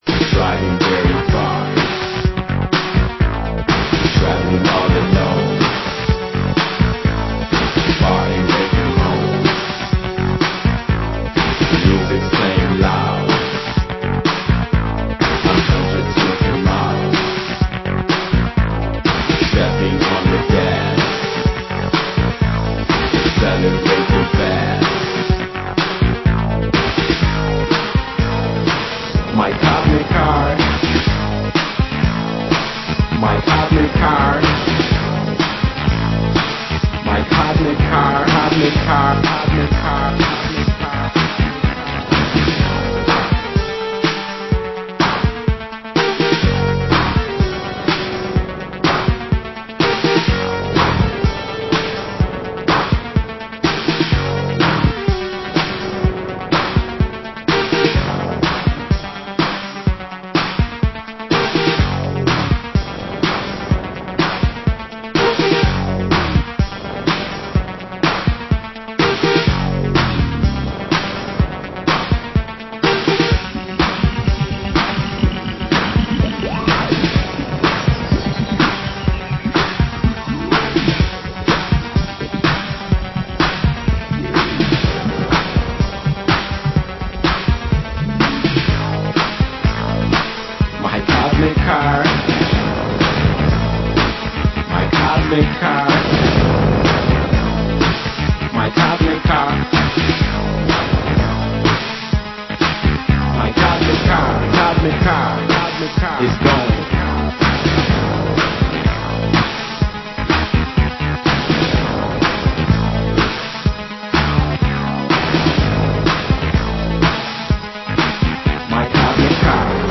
Genre: Old Skool Electro